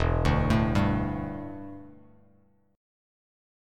Em11 chord